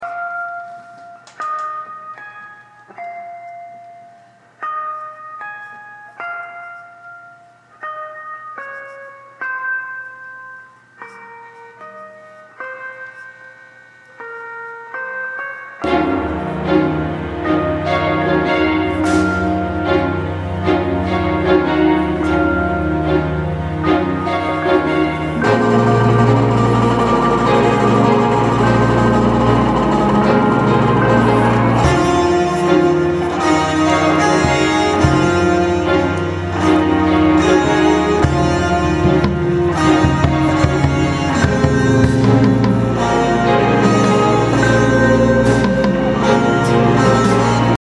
Expiremental music i made using sound effects free download
Expiremental music i made using garageband and my jazzmaster guitar.